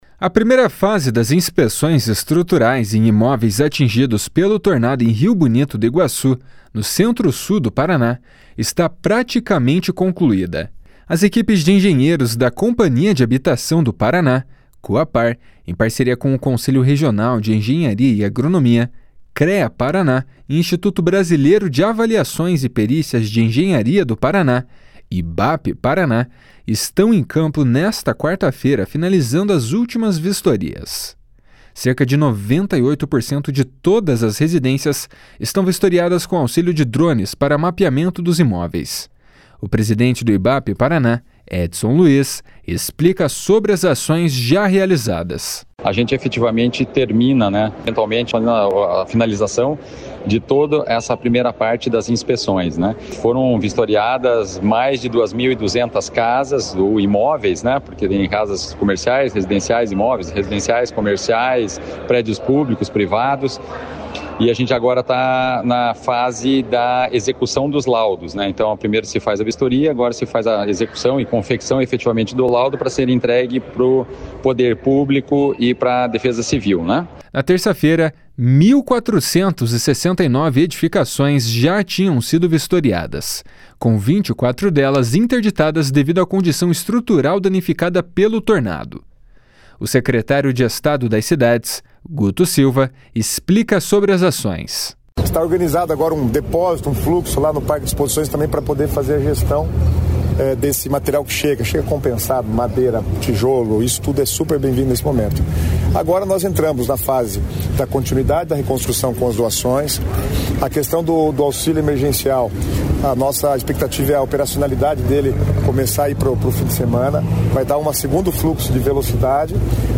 O secretário de Estado das Cidades, Guto Silva, explica sobre as ações.